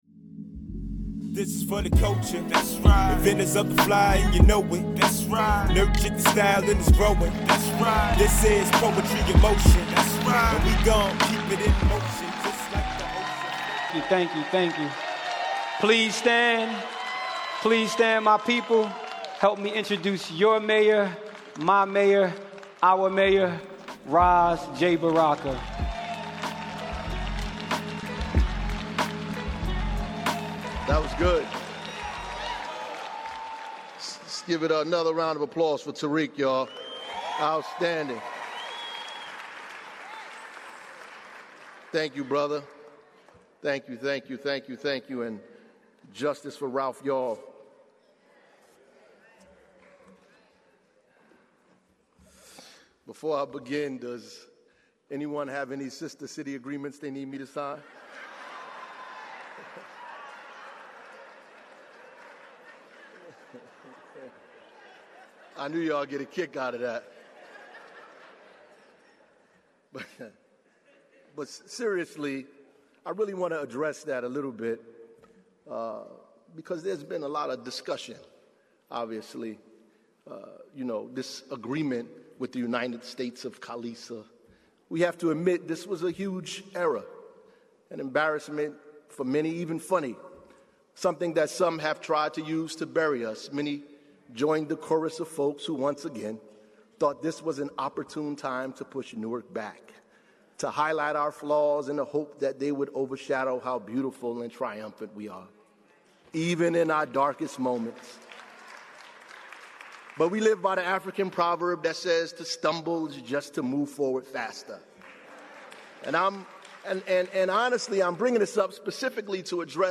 Listen to Mayor Baraka as he delivers his ninth State of the City address. Before an audience of residents, stakeholders, elected officials, and media representatives, Mayor Baraka described the life-changing initiatives his administration has put in place over the past nine years.